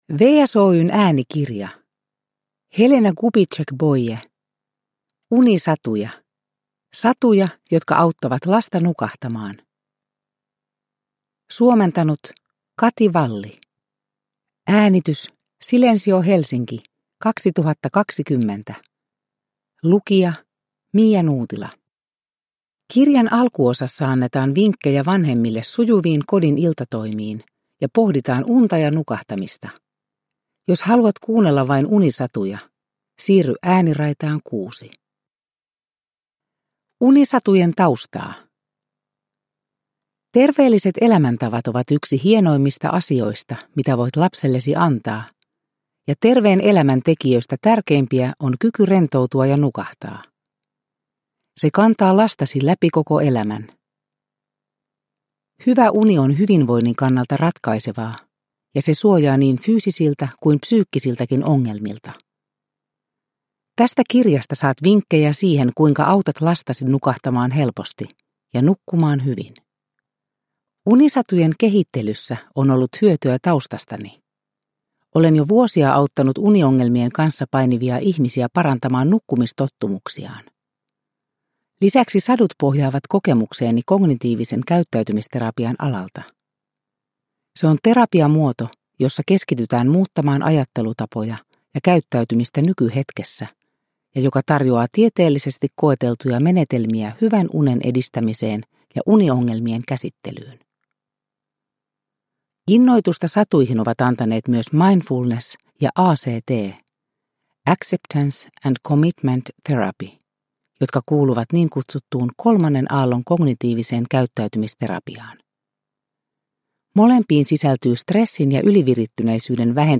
Loppuosan viidessä, kauniissa unisadussa on rauhallinen ja tauotettu luenta, jonka avulla tunnelma iltasadun äärellä rauhoittuu kohti unen tuloa.